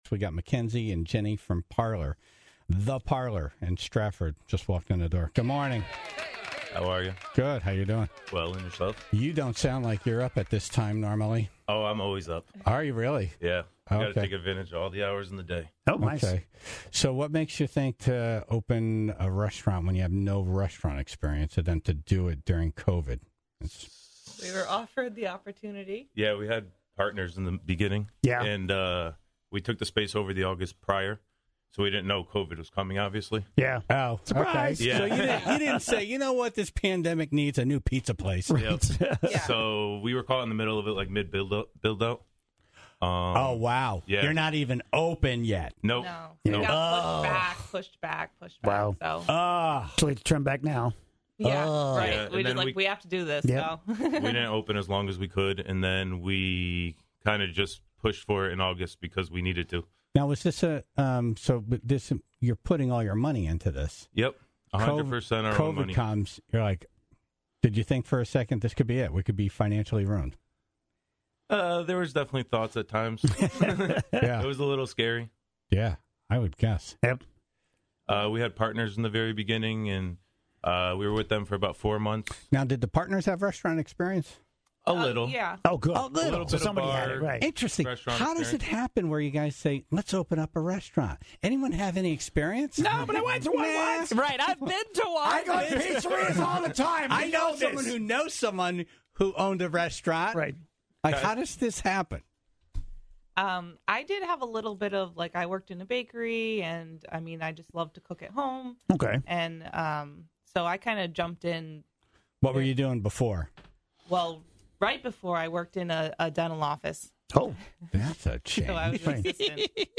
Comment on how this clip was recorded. in studio this morning to talk about the crazy idea to open a restaurant with no former experience, and to do it during the pandemic.